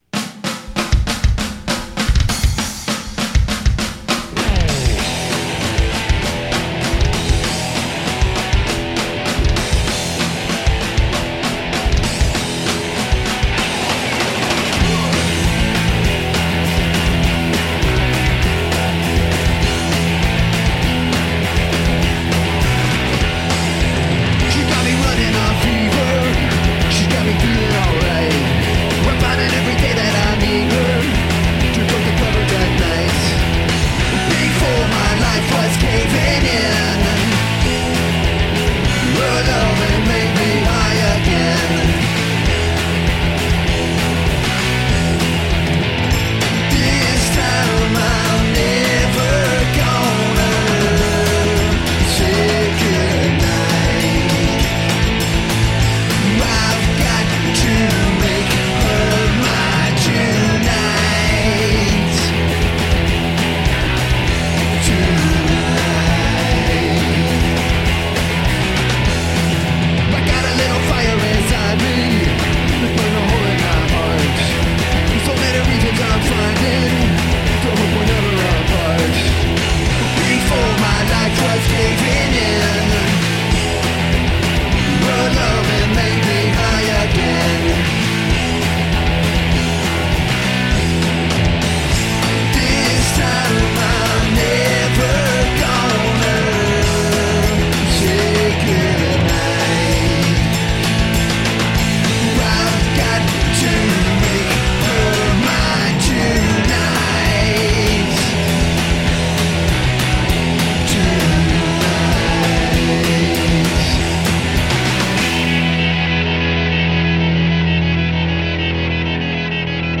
High energy rock and roll.
Tagged as: Hard Rock, Metal, Punk, High Energy Rock and Roll